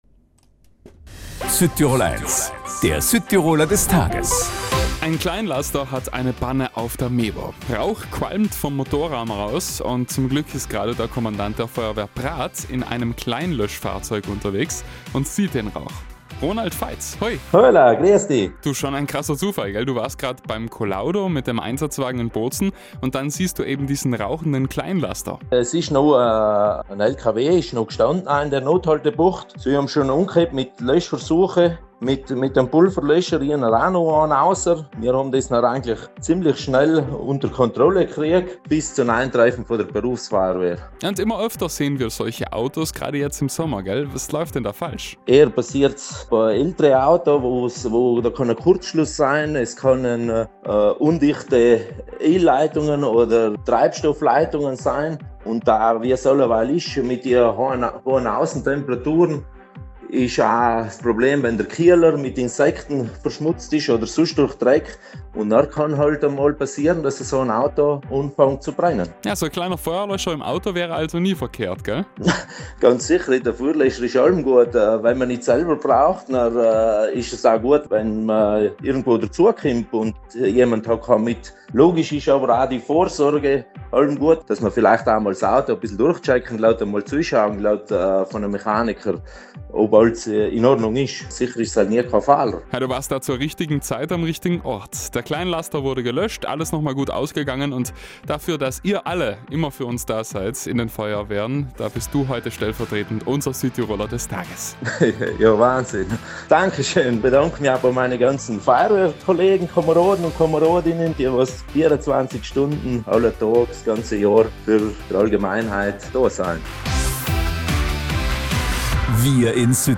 Schnell sichern die Feuerwehrleute die Stelle und löschen mit einem Feuerlöscher den Motorraum des Kleinlasters. Wie nützlich ein kleiner Feuerlöscher im Auto sein kann, das erzählt uns unser Südtiroler des Tages im Interview: